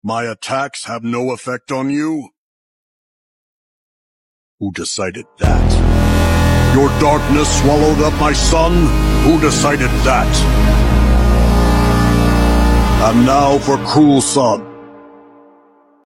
who decided that escanor Meme Sound Effect
Category: Anime Soundboard